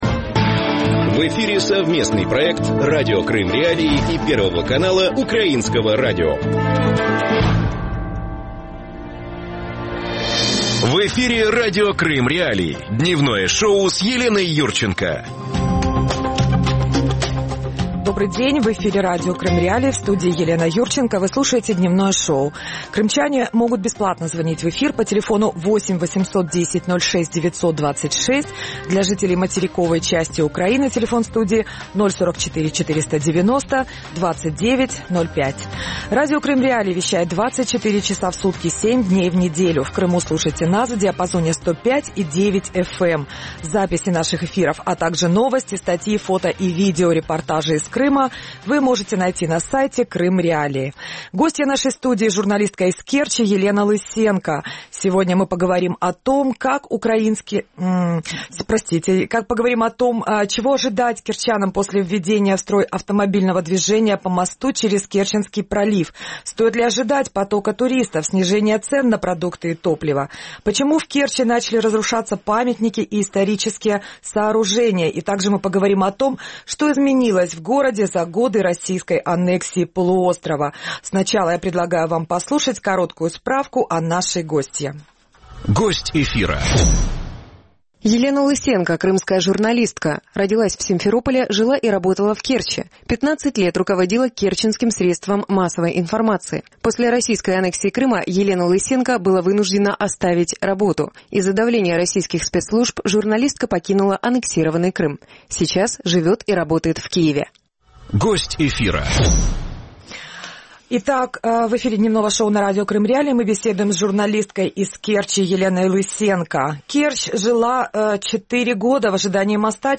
Об этом – в проекте «Дневное шоу» в эфире Радио Крым.Реалии с 12:10 до 12:40.